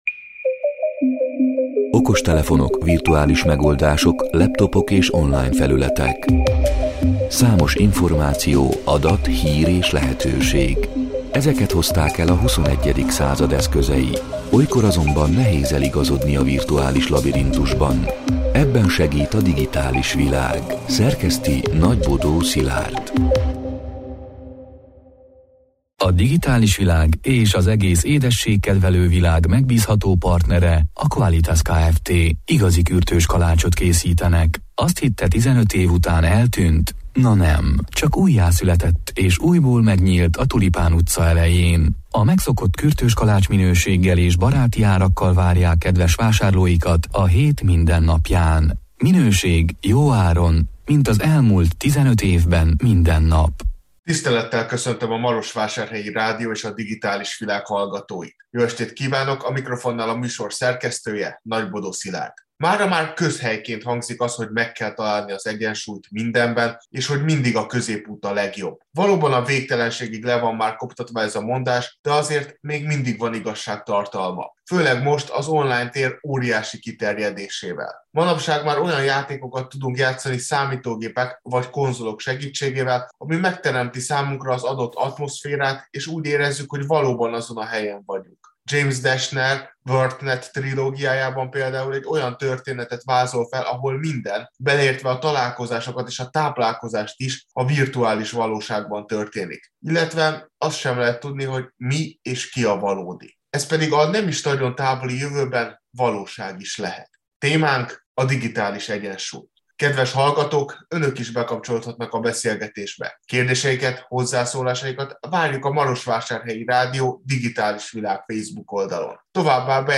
A Marosvásárhelyi Rádió Digitális Világ (elhangzott: 2022. április 12-én, kedden este nyolc órától) c. műsorának hanganyaga: Mára már közhelyként hangzik az, hogy meg kell találni az egyensúlyt mindenben és hogy mindig a középút a legjobb.